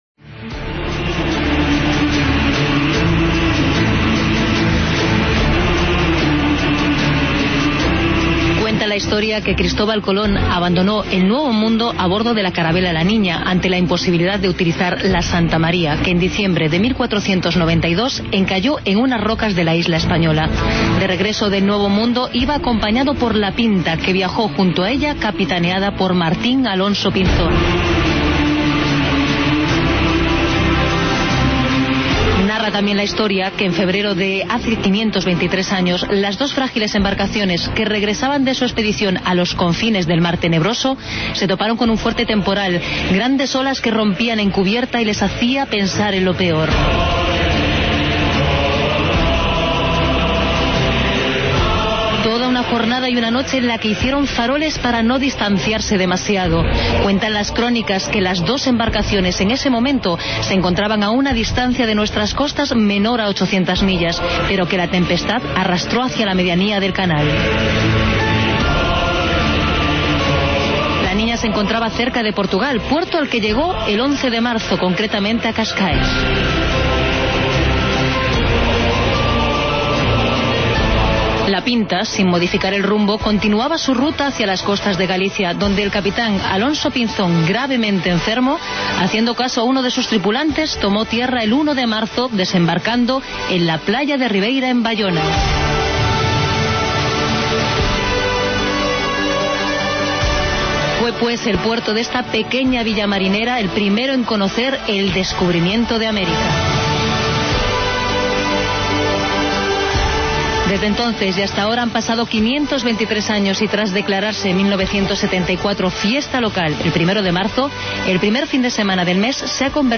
AUDIO: Programa especial desde el concello de Baiona, con motivo de la fiesta de la Arribada.